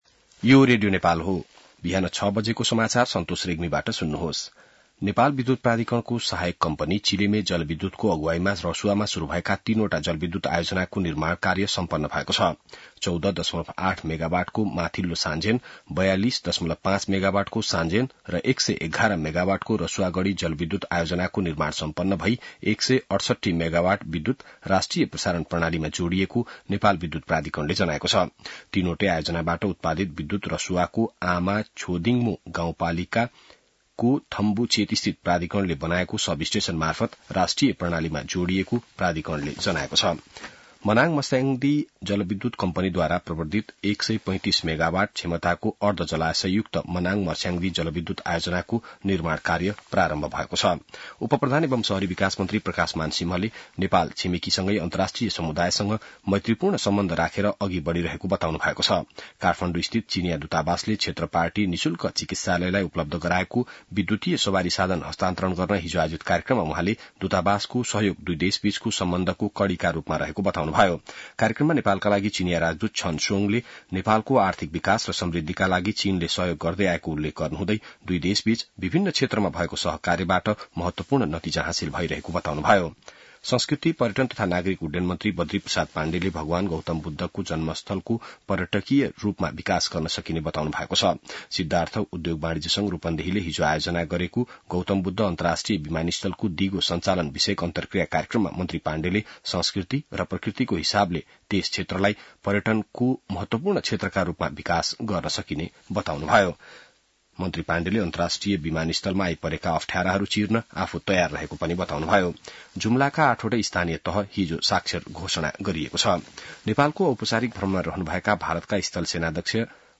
बिहान ६ बजेको नेपाली समाचार : १० मंसिर , २०८१